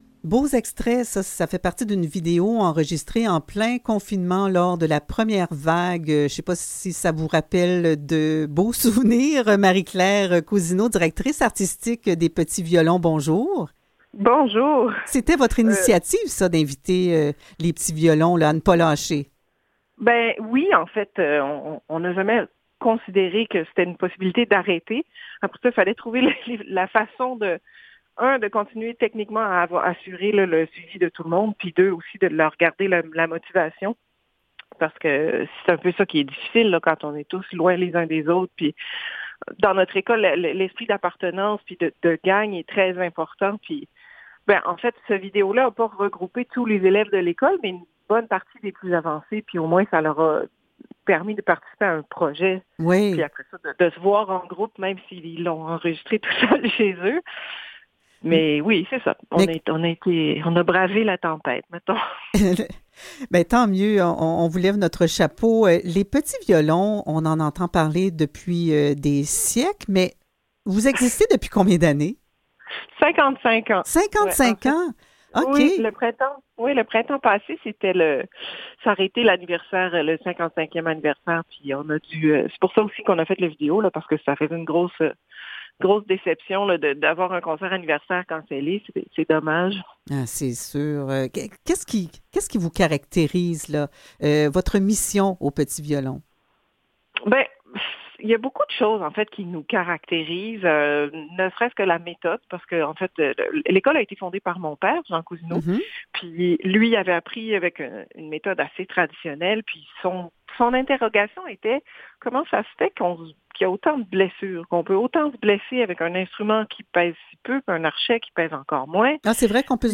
Revue de presse et entrevues du 17 décembre